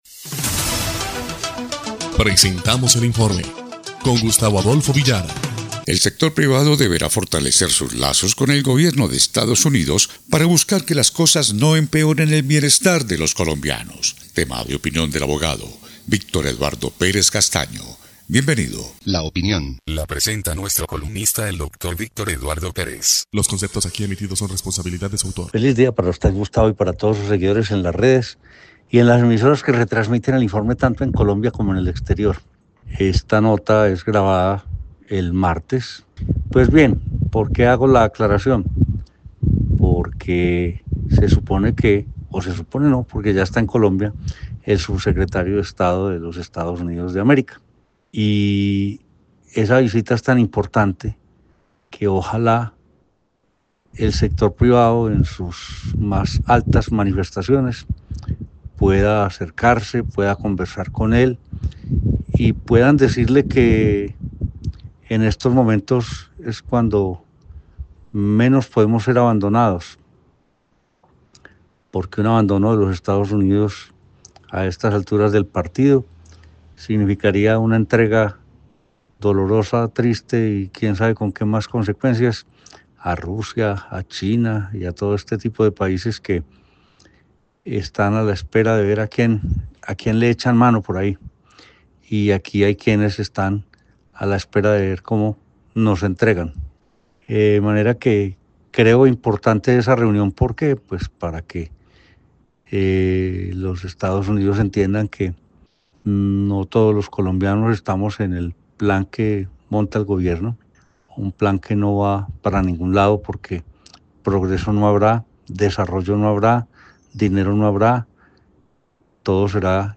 EL INFORME 1° Clip de Noticias del 14 de agosto de 2025